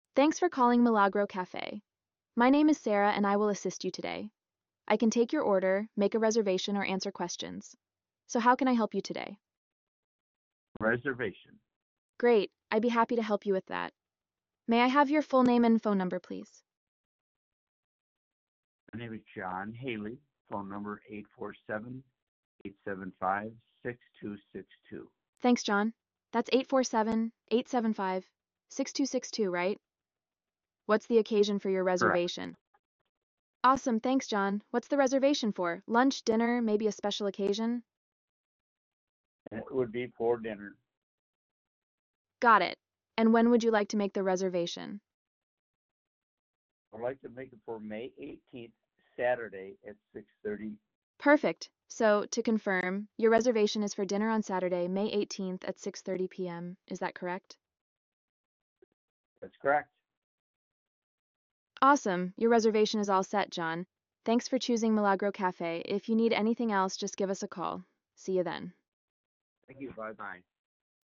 Listen to a sample conversation between Vivant’s Voice AI restaurant ordering system and a customer.